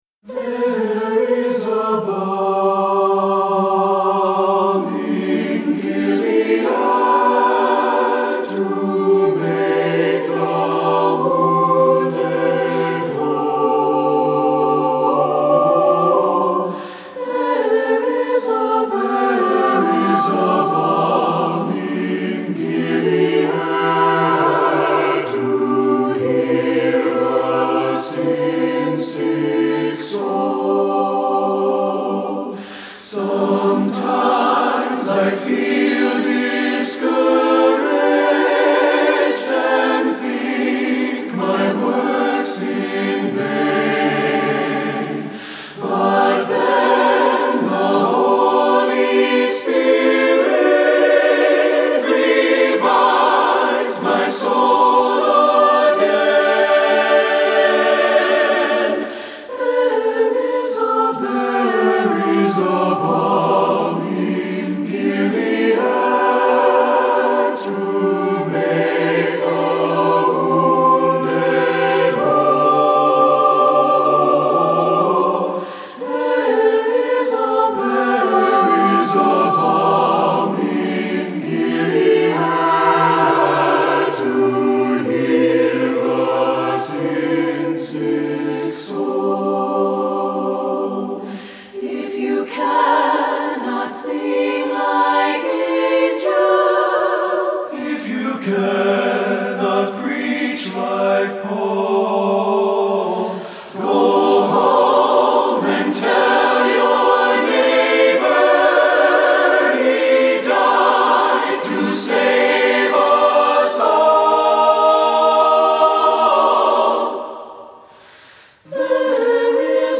Composer: Traditional Spiritual
Voicing: SATB a cappella